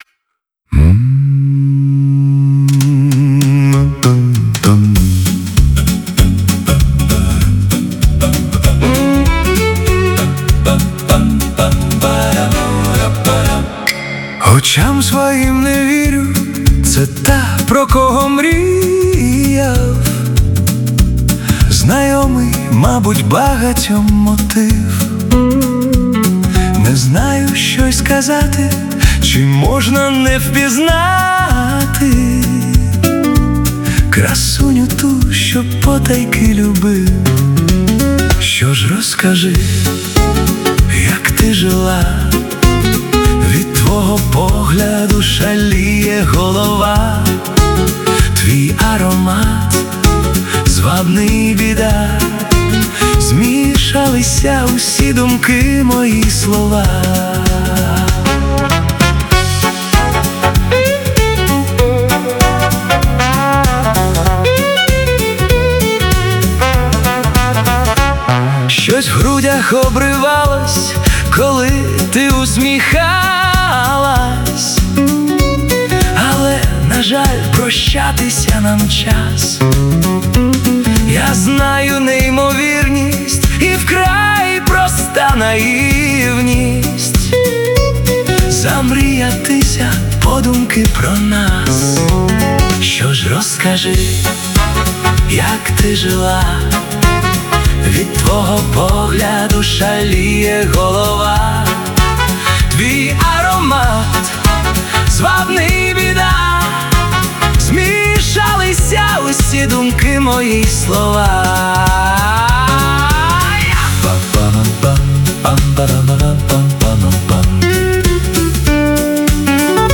Стиль: Фолк-поп